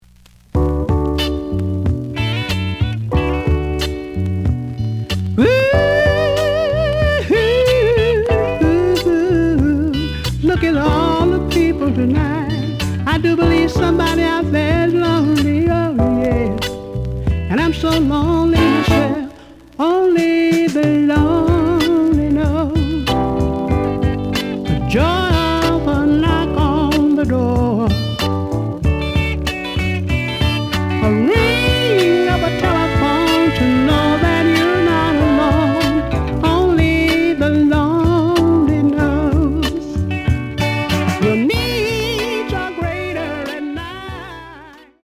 The audio sample is recorded from the actual item.
●Genre: Funk, 70's Funk
Looks good, but slight noise on B side.